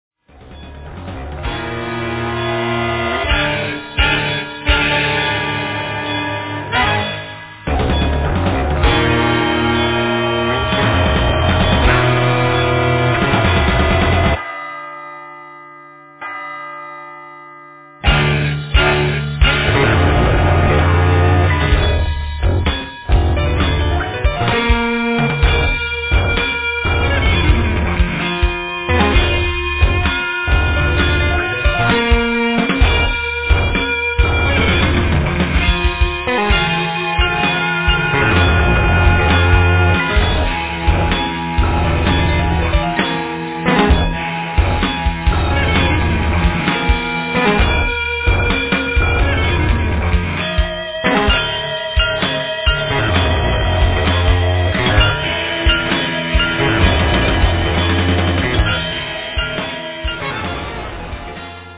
composition, vocals, direction, guitar
drums, percussion, vocals
piano, keyboards, mellotron, vocals
trumpet, flugelhorn
accordion